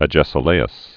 (ə-jĕsə-lāəs) 444?-360?